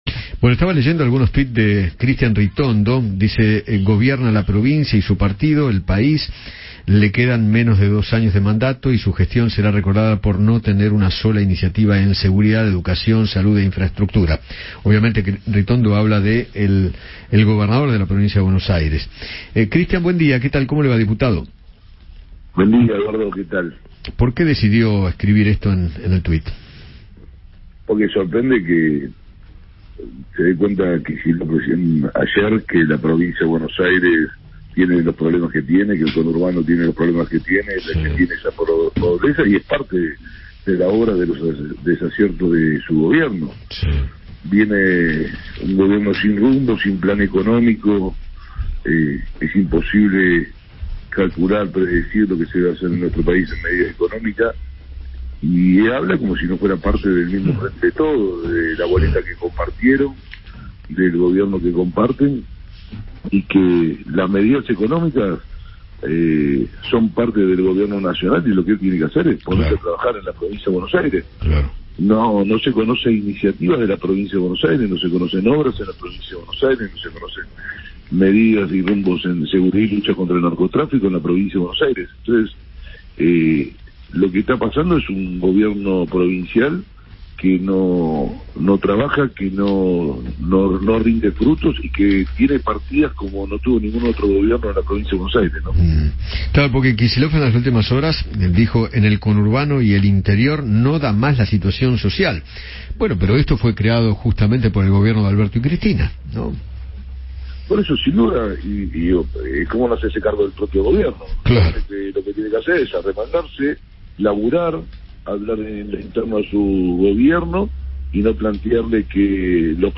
Cristian Ritondo, diputado de Juntos por el Cambio, dialogó con Eduardo Feinmann acerca de los dichos de Axel Kicillof sobre la inflación y analizó la gestión del Frente de Todos en estos dos años y medio de mandato.